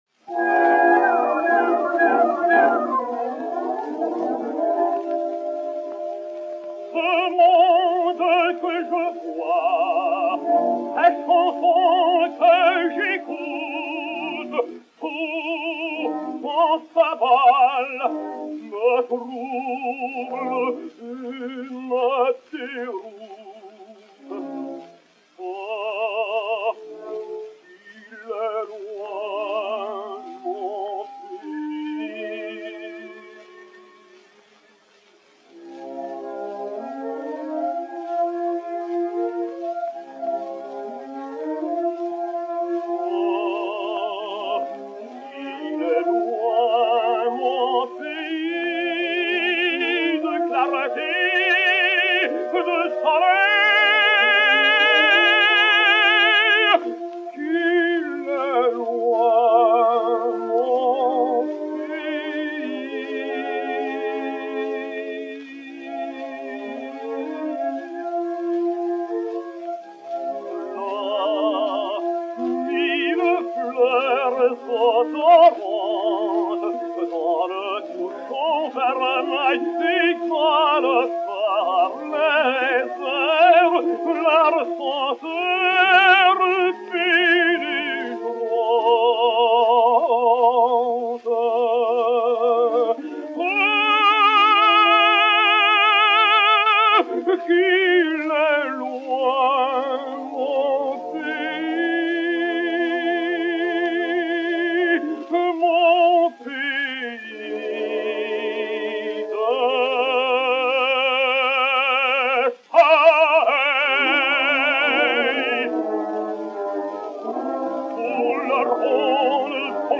Léon Campagnola (Jean) et Orchestre